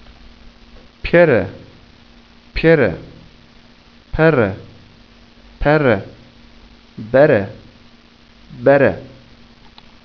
Minimalpaare
Labiale vor e-Vokalen / r-Laute
(Pülümür 1)